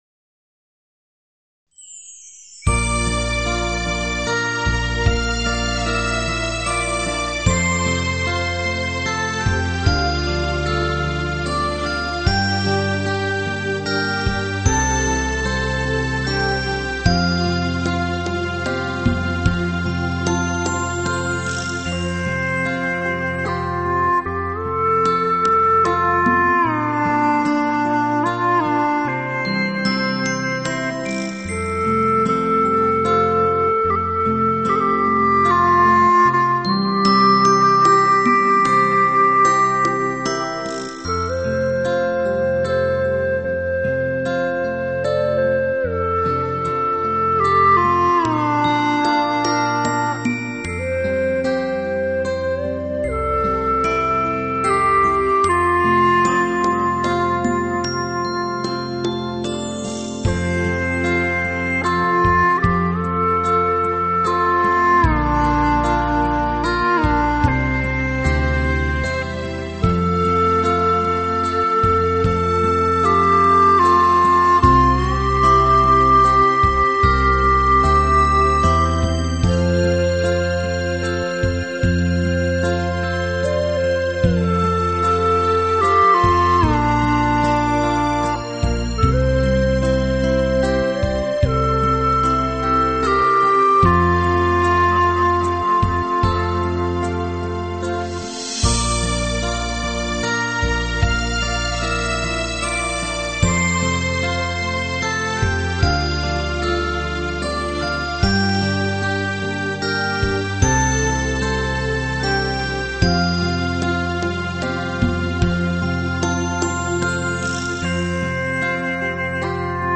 丝丝奏出美妙音韵